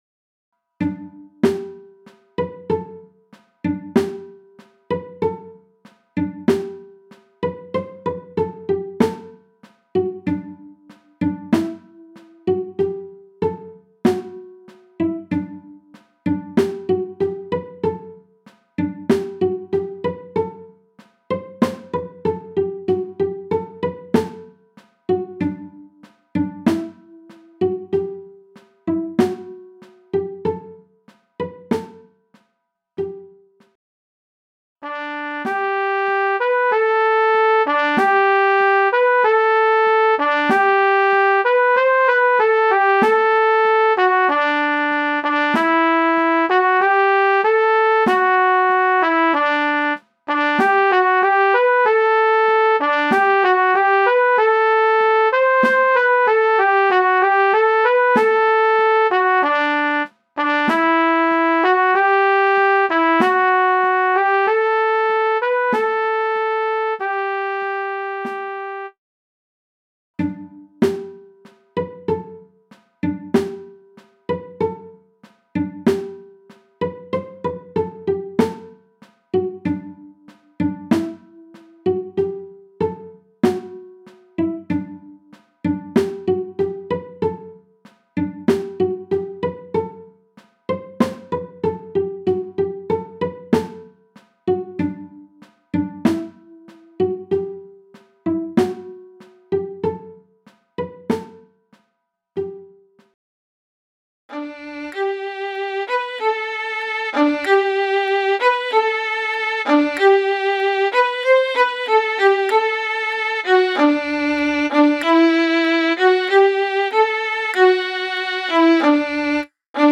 MIDI von 2012 [6.902 KB] - mp3